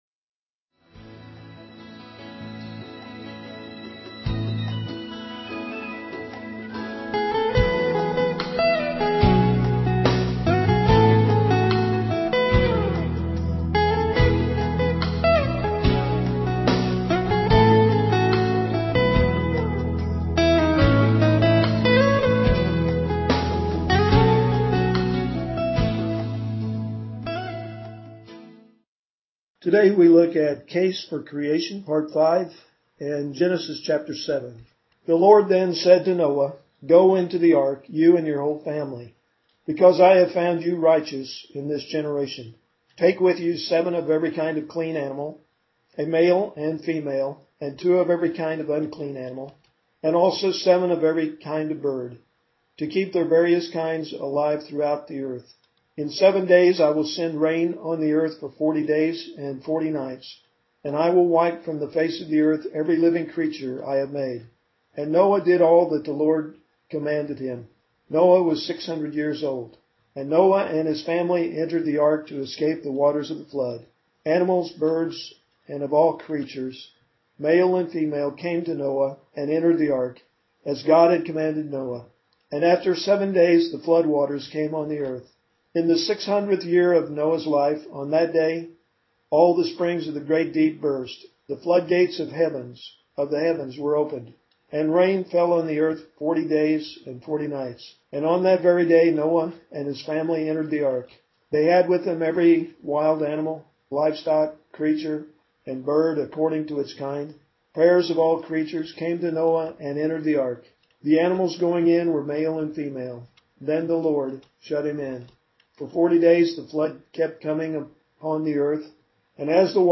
at Ewa Beach Baptist Church. Musical Intro/Outro